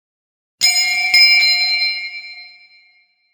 FX METAL CLANKING ON CONCRETE 501
bang clang clank clunk ding metal metallic steel sound effect free sound royalty free Sound Effects